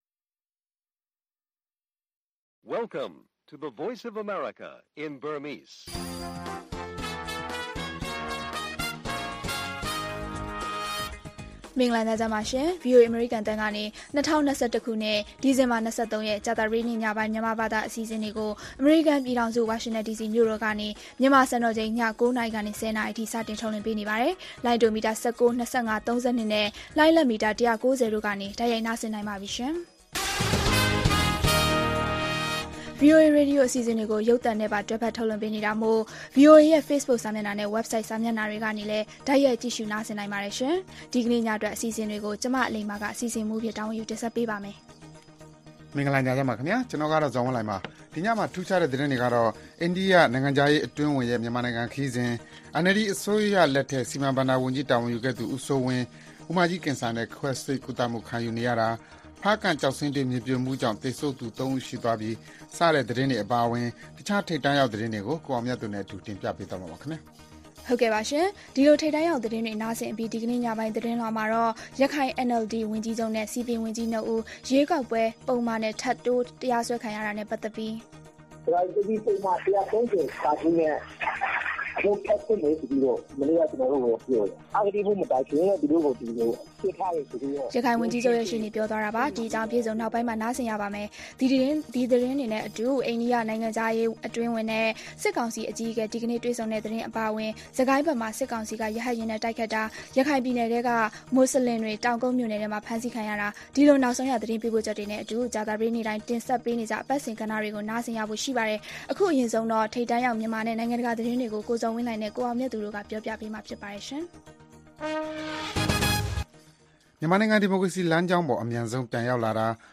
ဗွီအိုအေ ကြာသပတေးည ၉း၀၀-၁၀း၀၀ နာရီ ရေဒီယို/ရုပ်သံလွှင့် အစီအစဉ်